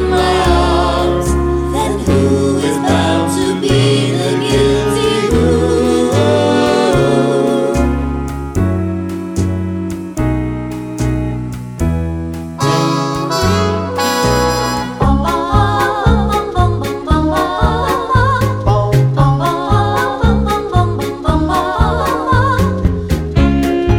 Down 3 Semitones Pop (1950s) 2:25 Buy £1.50